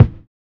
LO FI 6 BD.wav